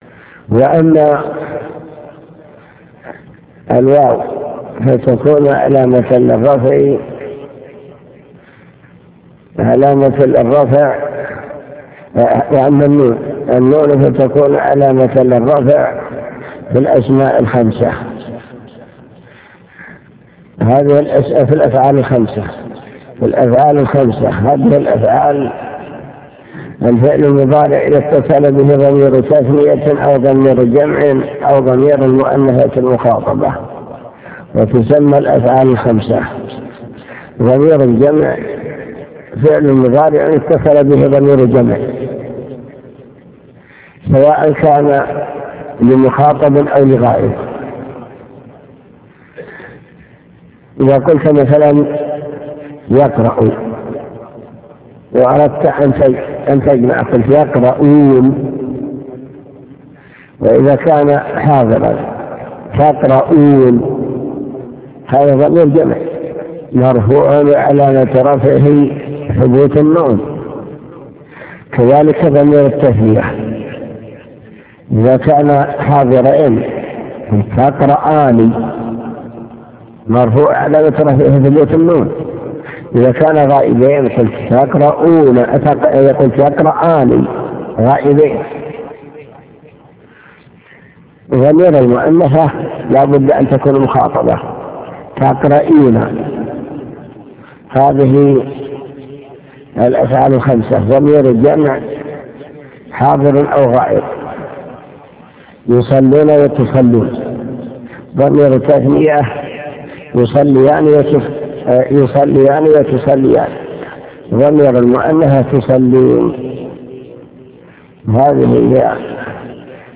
المكتبة الصوتية   تسجيلات - كتب  شرح كتاب الآجرومية  موقع سماحة الشيخ بن جبرين-رحمه الله..شرح كتاب الآجرومية